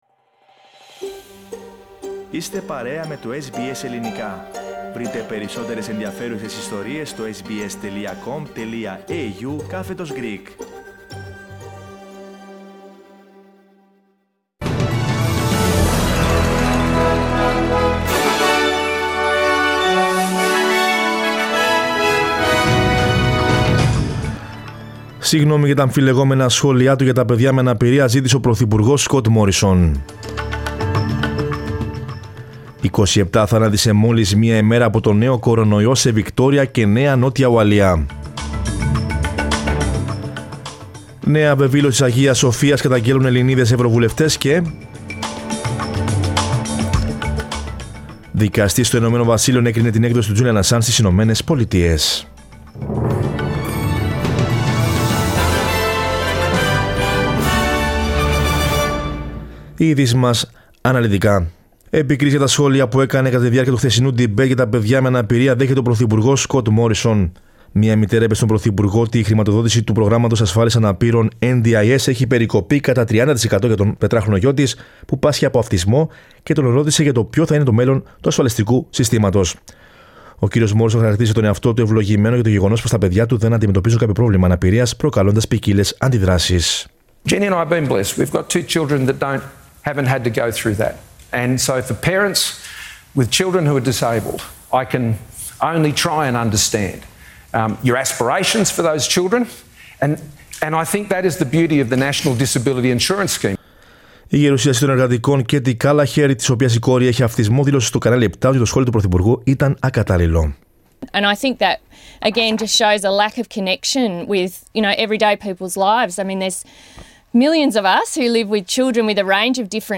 Δελτίο Ειδήσεων Μ. Πέμπτη 21.04.22